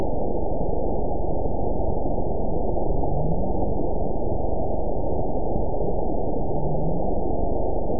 event 910157 date 01/16/22 time 07:31:27 GMT (3 years, 4 months ago) score 9.26 location TSS-AB05 detected by nrw target species NRW annotations +NRW Spectrogram: Frequency (kHz) vs. Time (s) audio not available .wav